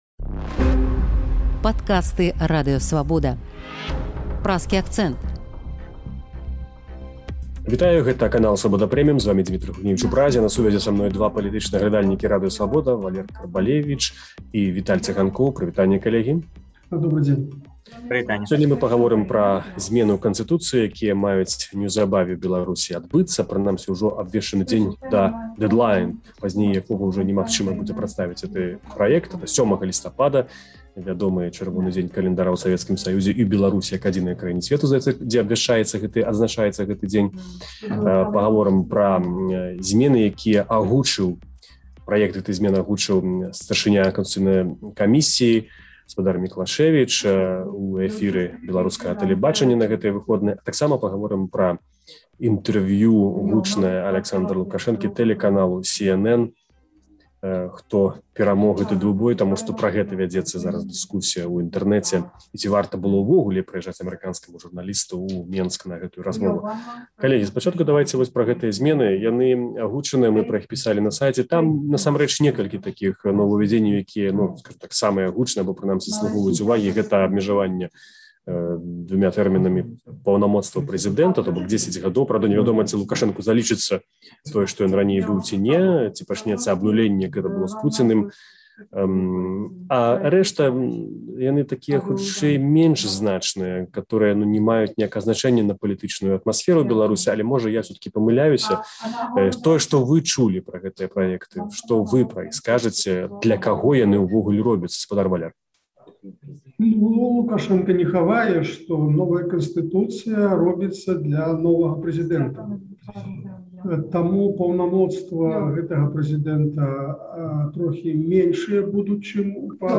Праект новай Канстытуцыі Беларусі будзе апублікаваны не пазьней за 7 лістапада, а рэфэрэндум аб яе прыняцьця адбудзецца не пазьней за люты наступнага году. Палітычныя аглядальнікі Свабоды